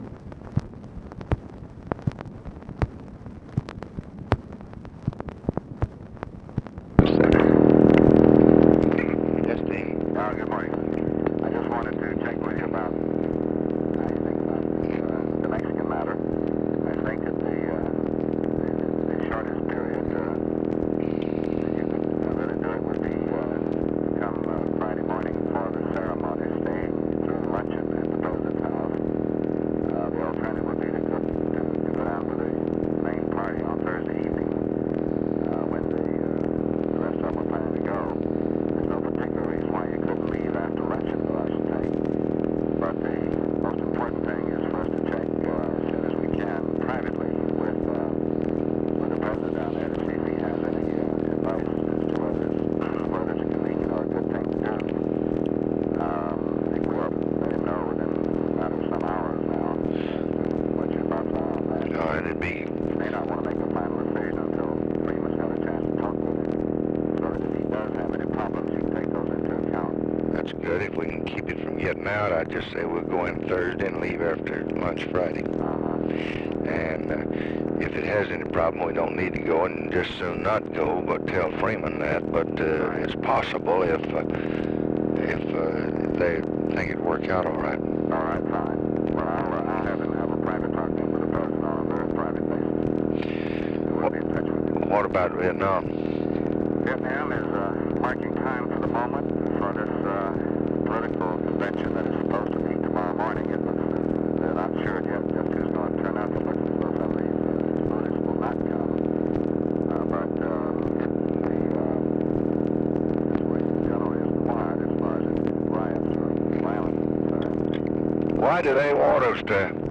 Conversation with DEAN RUSK, April 11, 1966
Secret White House Tapes